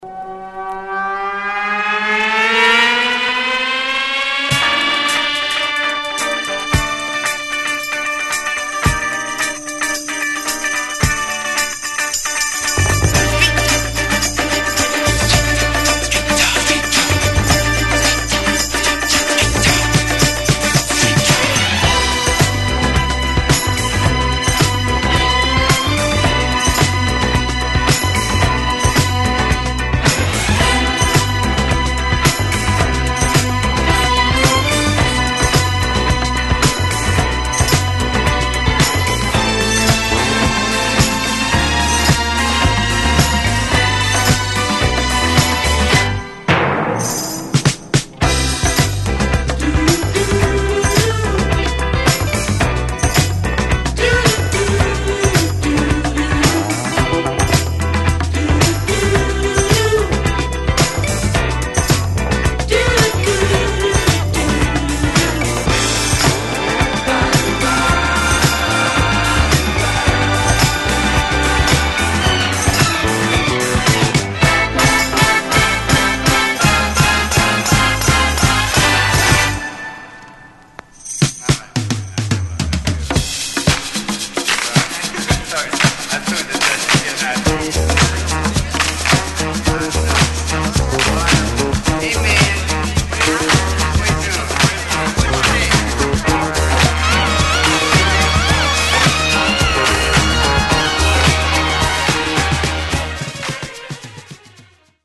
中盤のBreakも◎なセミ・インスト・ディスコ!!